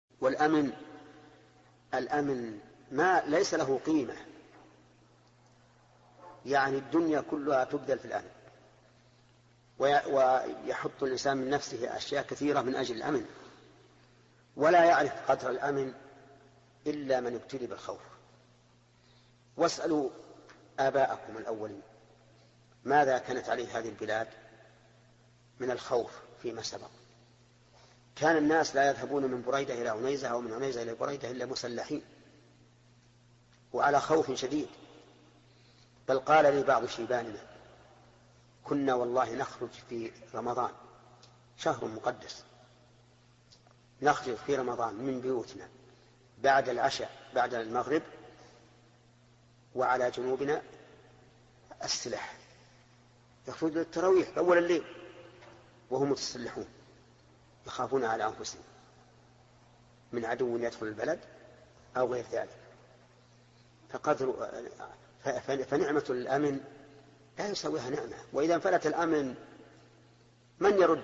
Download audio file Downloaded: 497 Played: 405 Artist: الشيخ ابن عثيمين Title: لا يعرف قدر الأمن إلا من ابْتُلِيَ بالخوف Album: موقع النهج الواضح Length: 1:11 minutes (355.65 KB) Format: MP3 Mono 22kHz 32Kbps (VBR)